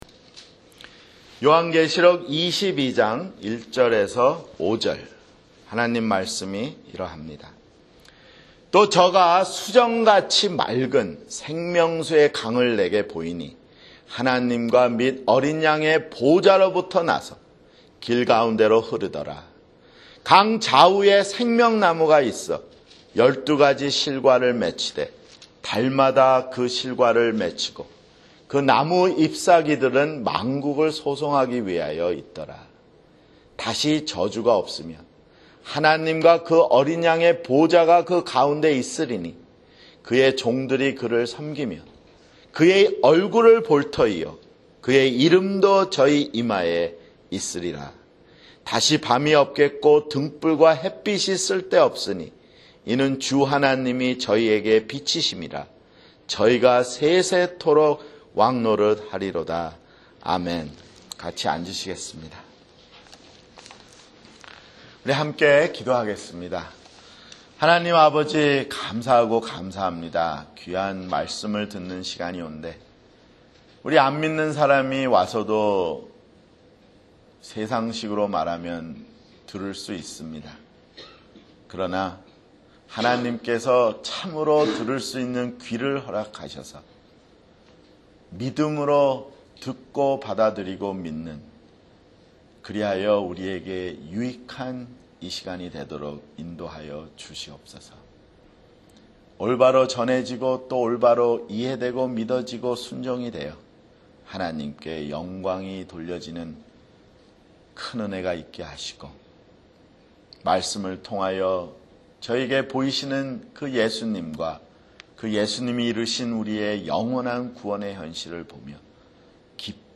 [주일설교] 요한계시록 (89)